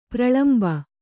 ♪ praḷamba